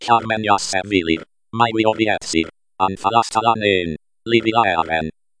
By espeak-ng TTS
abc-hyarmen-Espeak.mp3